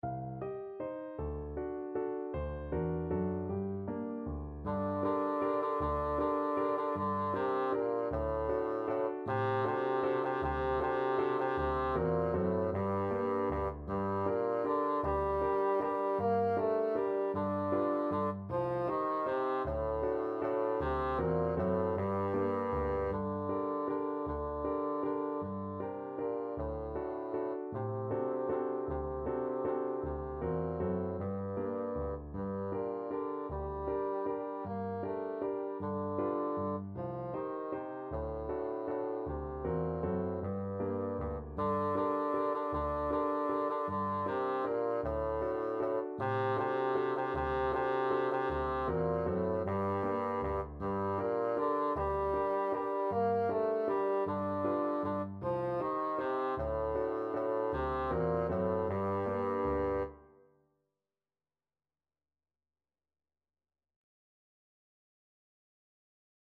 Traditional Trad. Tumbalalaika Bassoon version
Bassoon
Tumbalalaika is a Russian Jewish folk and love song in the Yiddish language.
G minor (Sounding Pitch) (View more G minor Music for Bassoon )
3/4 (View more 3/4 Music)
One in a bar . = c.52
Traditional (View more Traditional Bassoon Music)